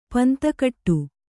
♪ panta kaṭṭu